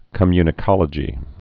(kə-mynĭ-kŏlə-jē)